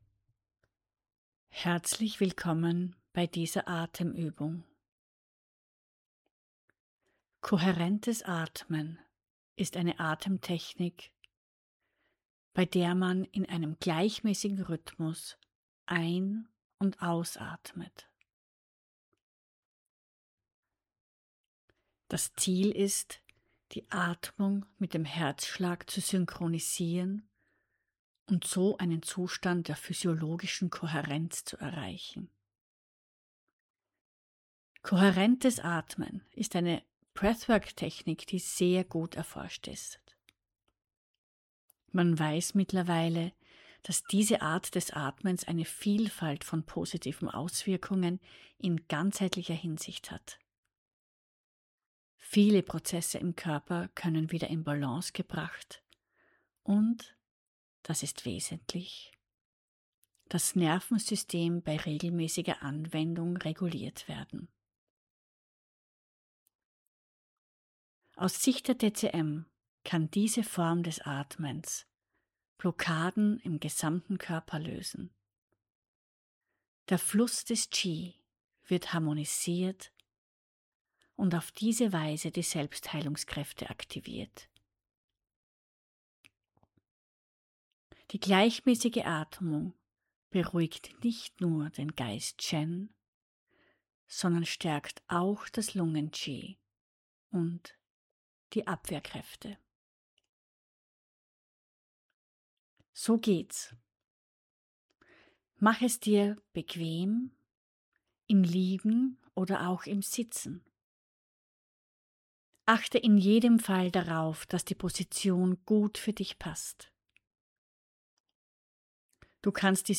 Meditation kohärentes Atmen
kohaerentes-atmen.mp3